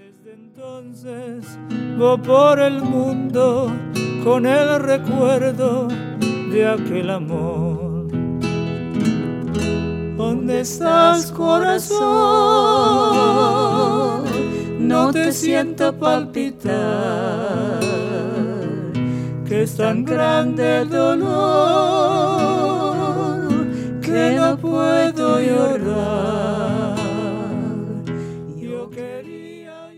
A vibrant, alive collection of Ladino duets
Folk